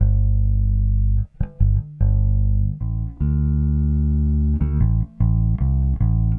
Weathered Bass 05.wav